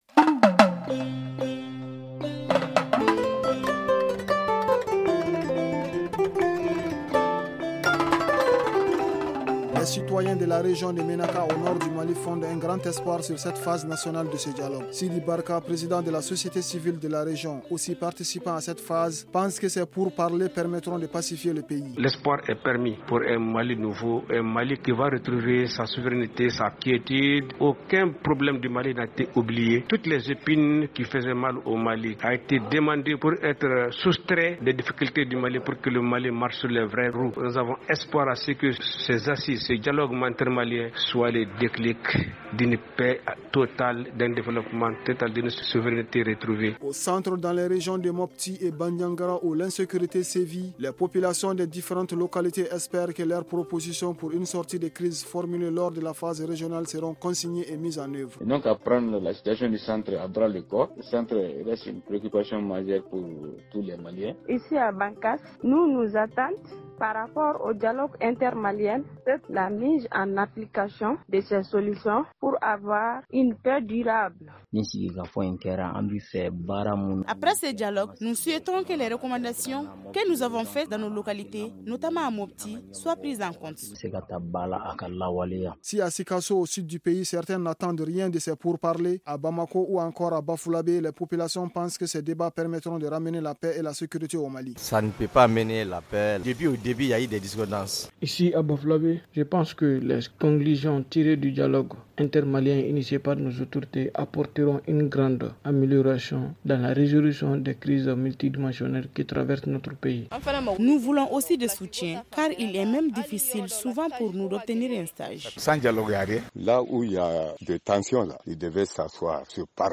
Le magazine du jour s’intéresse au dialogue inter-maliens.